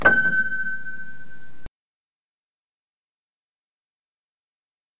标签： 黑暗 阴森恐怖 一次性 乱调 打击乐 钢琴
声道立体声